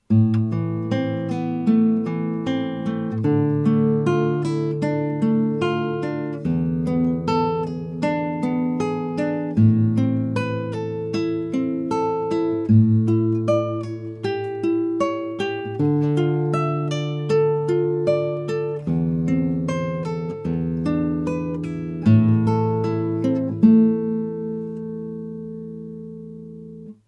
Number 10 is a powerful and even sounding large sized Classical guitar.
It has the following characteristics: Western Red Cedar top.
Recorded on a cheap USB microphone onto Audacity on my laptop without any equalization or modification.